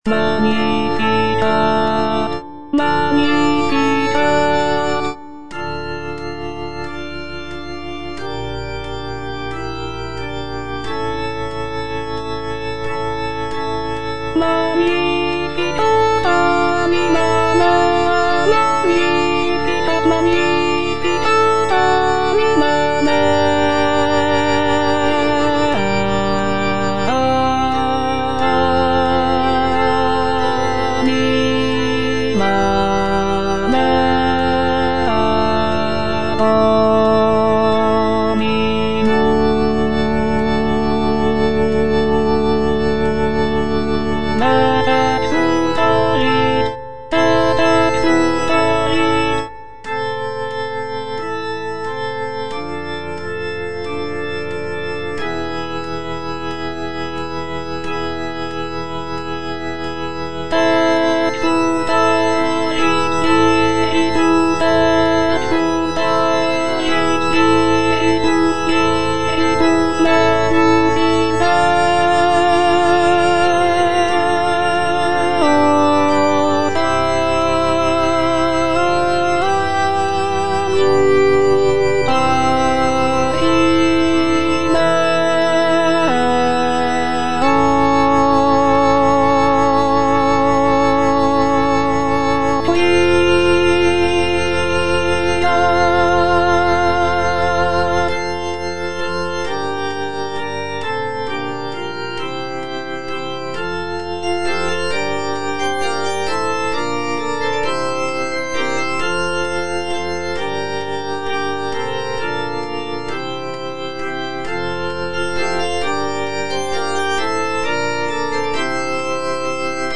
C. MONTEVERDI - MAGNIFICAT PRIMO (EDITION 2) Alto I (Voice with metronome) Ads stop: